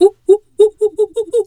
monkey_2_chatter_03.wav